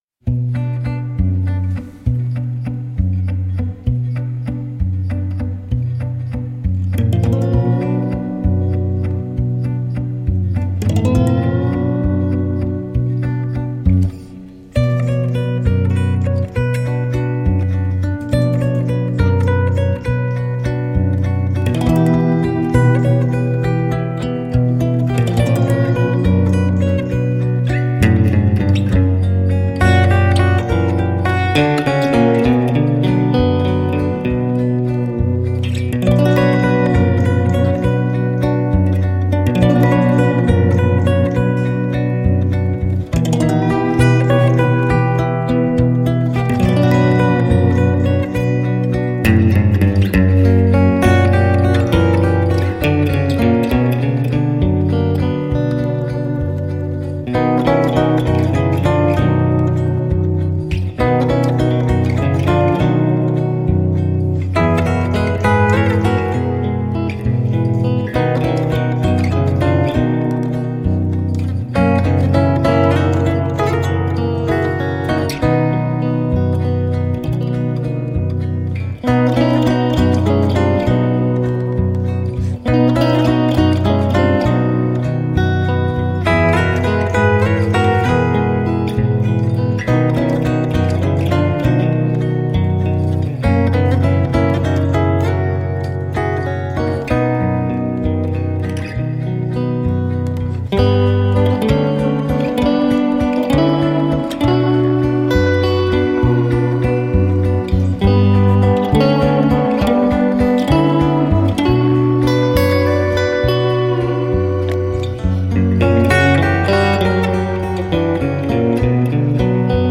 onde as guitarras são protagonistas absolutas.
Um fado reinventado pelas guitarras